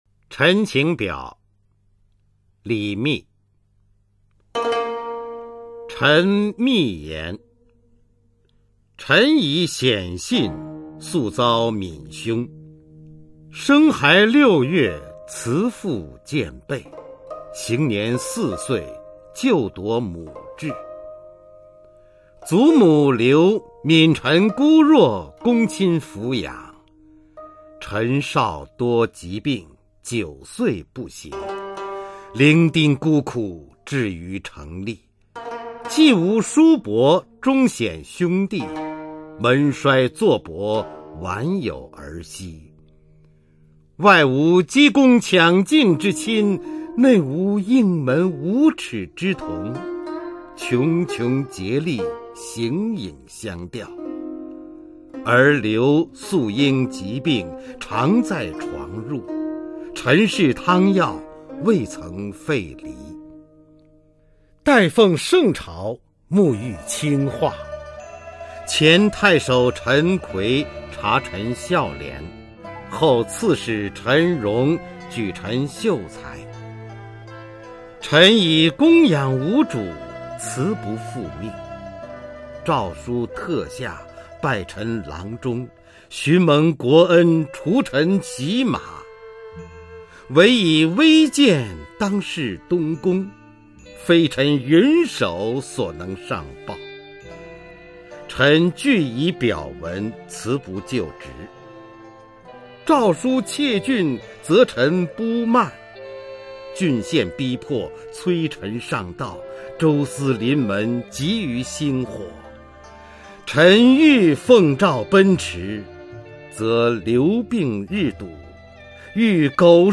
[魏晋诗词诵读]李密-陈情表 古诗朗诵